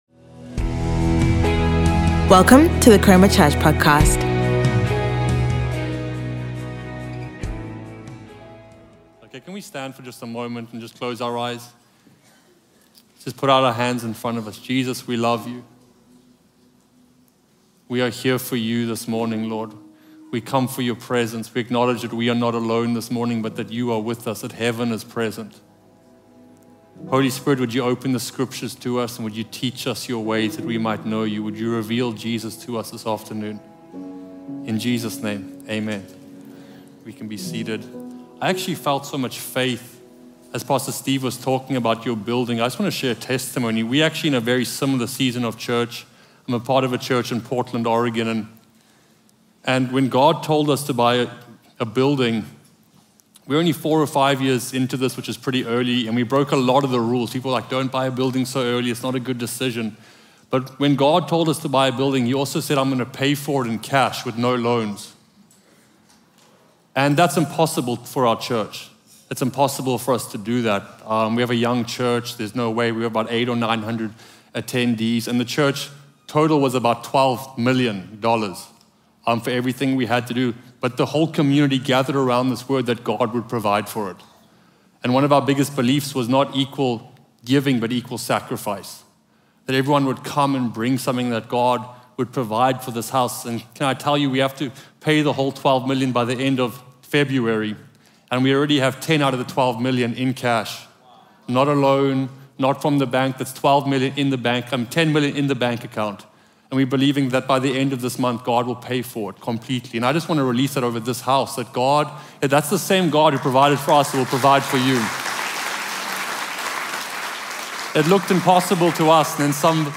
Sunday Sermon Pick Up Your Cross